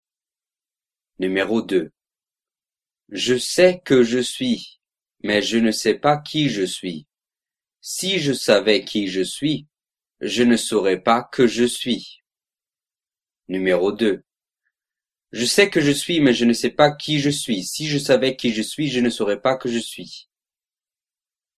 02 Virelangue